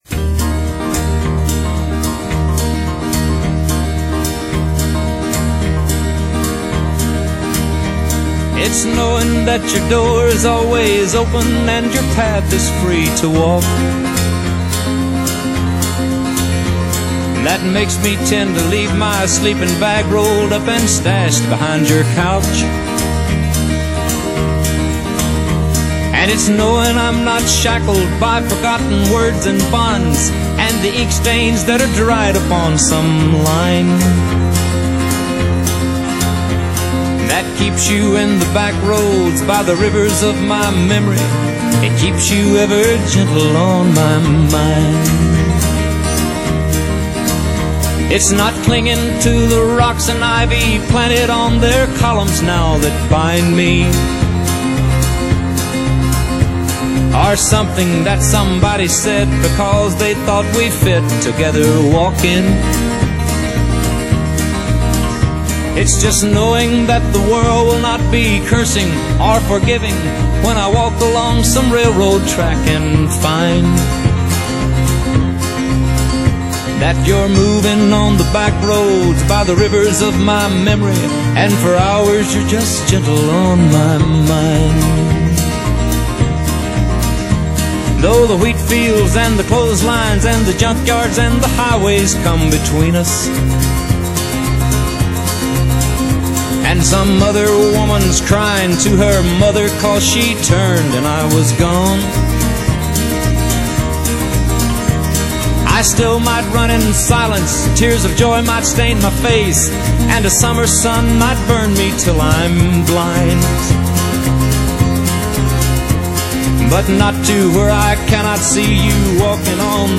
Country, Contemporary Pop/Rock, Country Rock